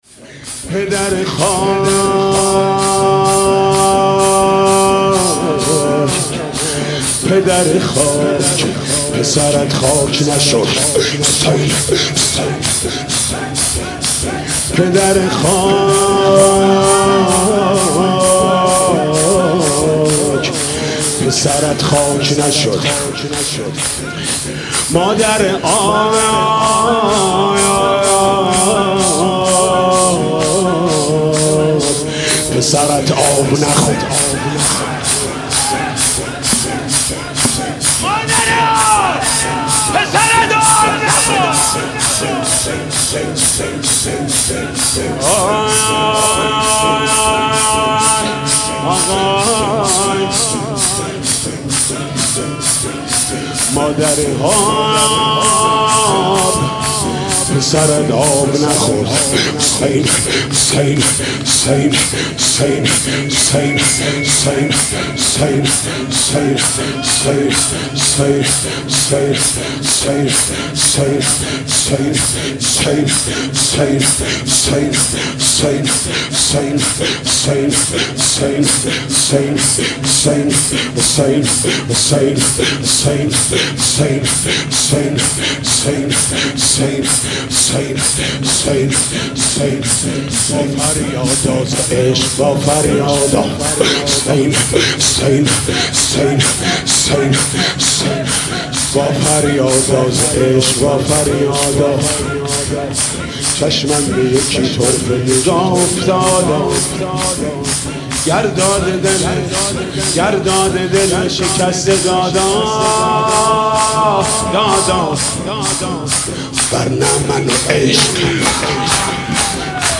«فاطمیه 1396» شور: پدر خاک، پسرت خاک نشد